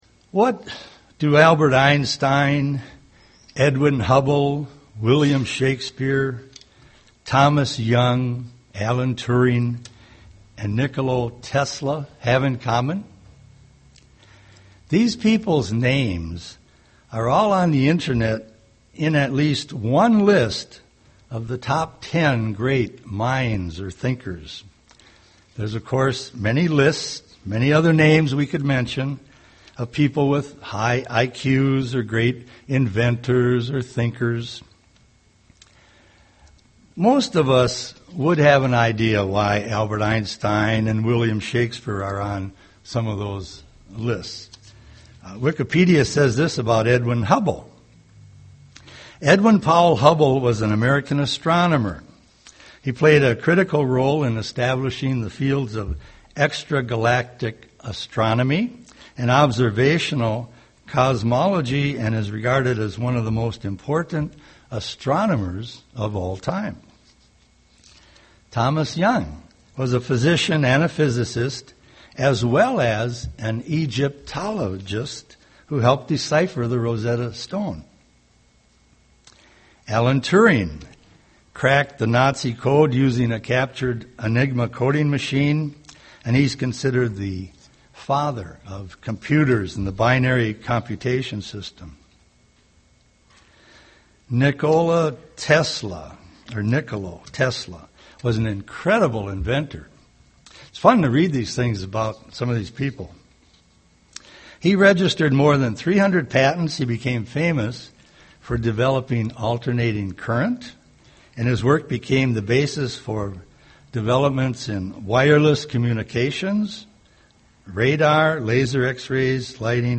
Given in Twin Cities, MN
UCG Sermon Christ living in you Studying the bible?